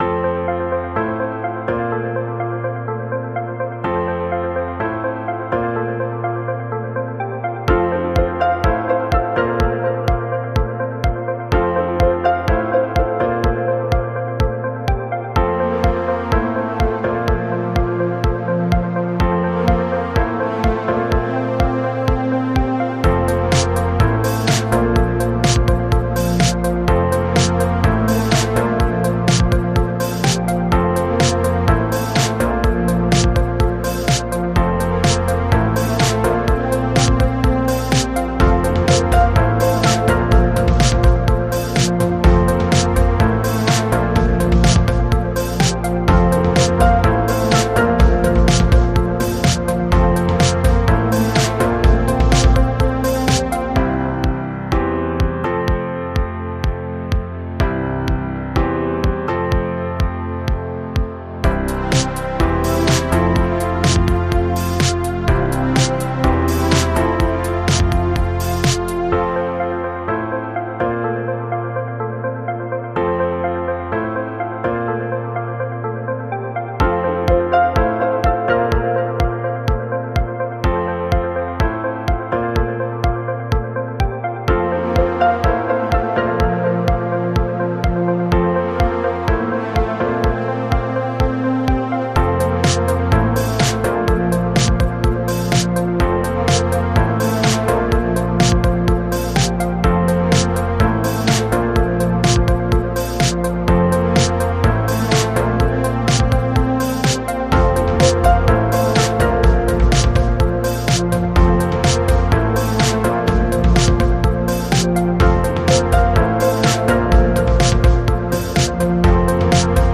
Motivational and inspiring music track